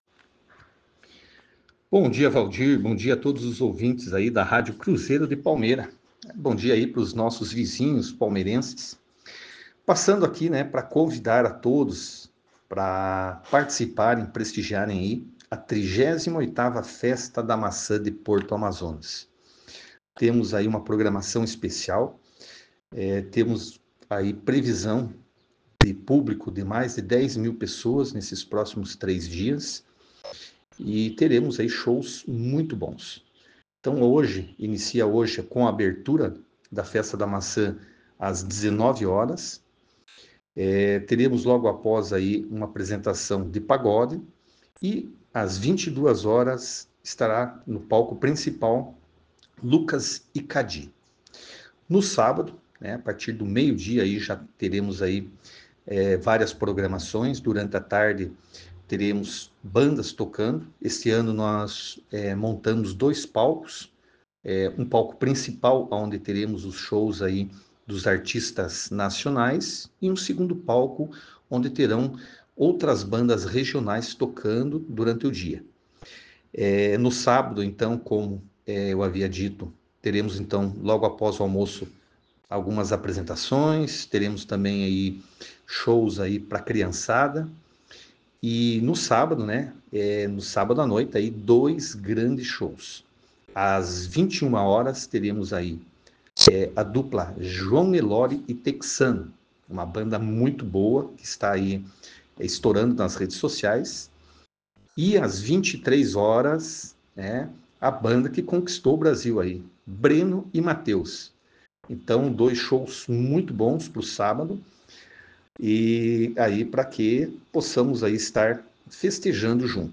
O prefeito de Porto Amazonas, Elias Gomes da Costa, em entrevista para CRUZEIRO FM convida a população local e de toda a região para prestigiar o evento: